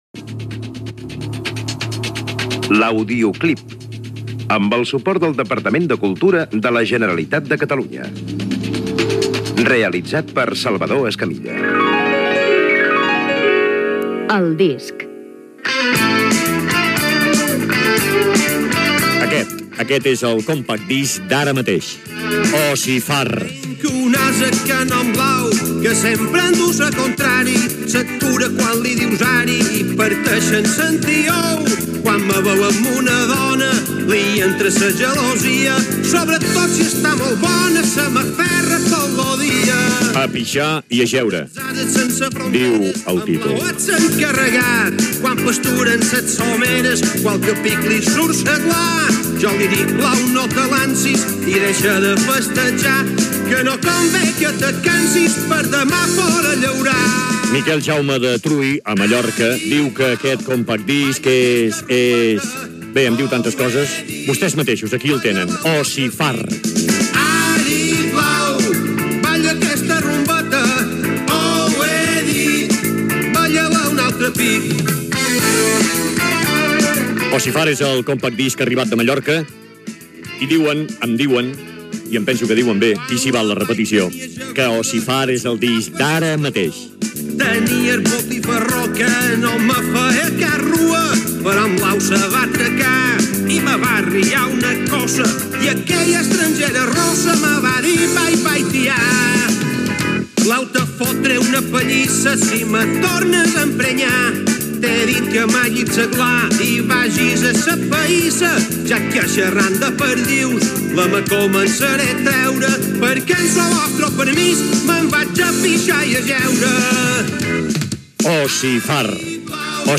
Careta del programa, informació del disc "A pixar i a jeure", del del grup mallorquí Ossafar; del llibre "El Darrer Buit" de Maria Carme Roca i del musical Festiva Roda, homenatge al saxofonista Ricard Roda